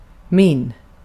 Ääntäminen
IPA : /ˈfeɪs/ US : IPA : [ˈfeɪs]